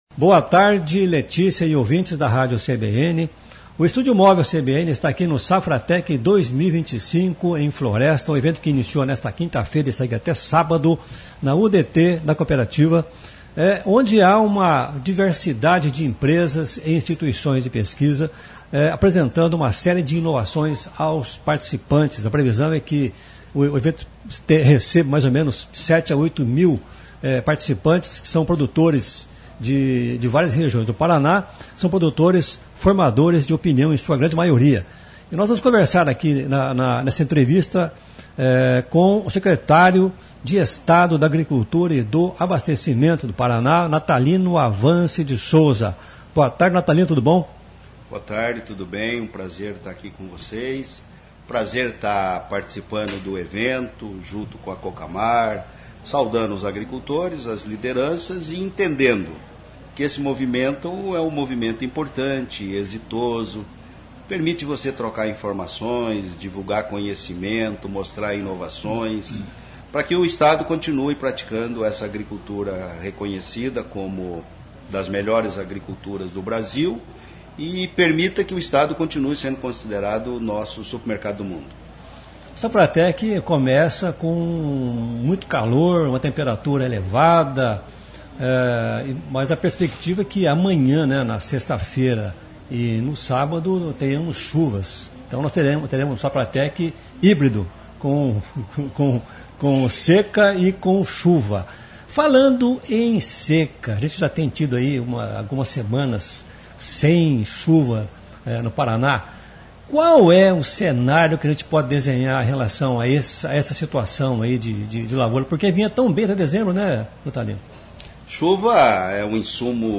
entrevista o secretário de Estado da Agricultura na Safratec 2025.